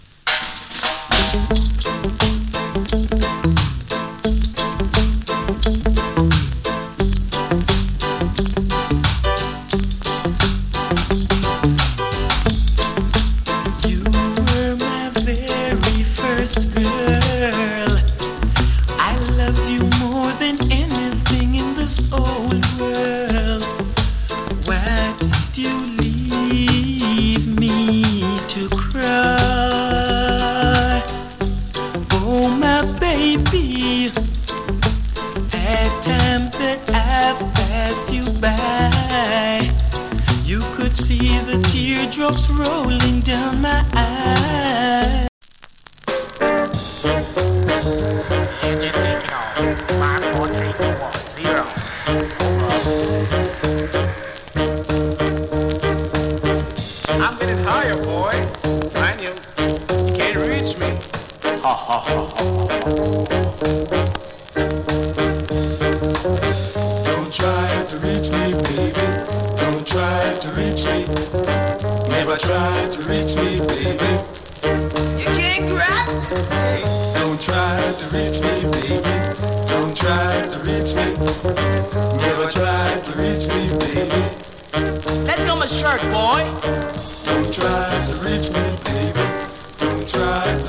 チリ、パチノイズ少し有り。プレスノイズ少し有り。B-SIDE はプレスノイズ強 !
& KILLER ROCK STEADY !